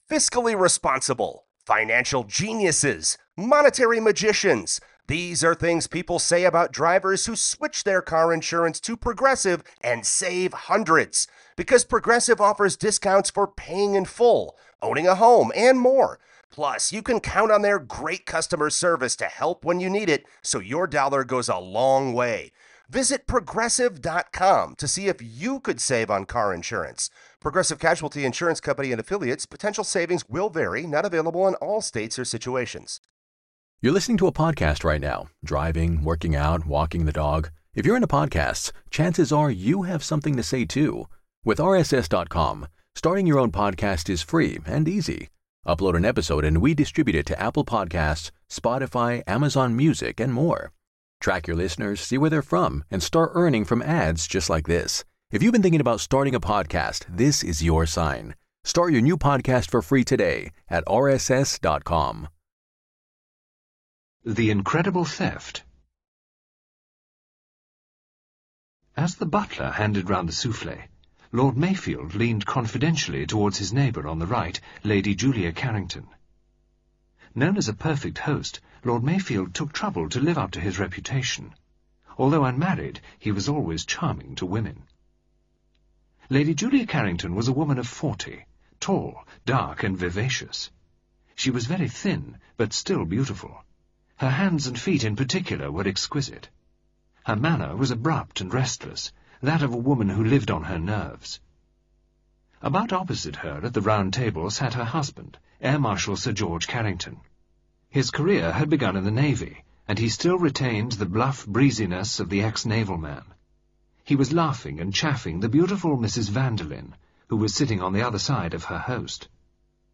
Audiobook Collection